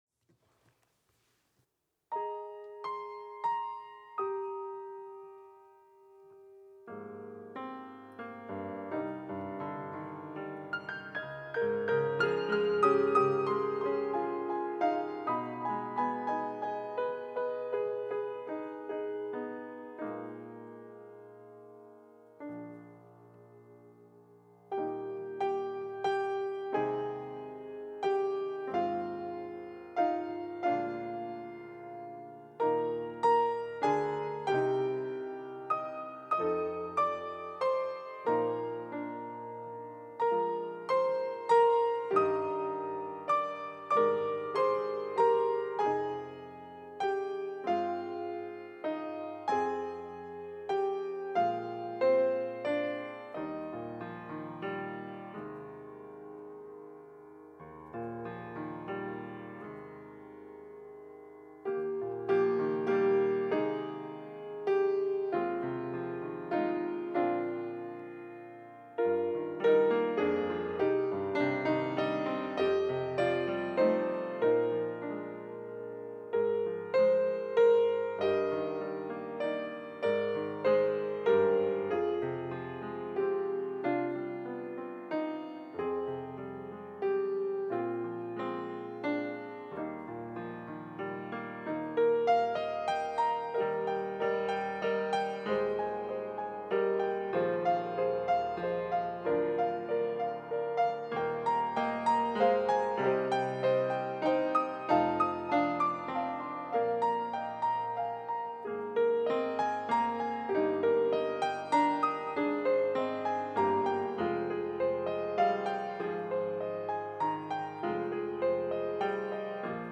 특송과 특주 - 겸손히 주를 섬길 때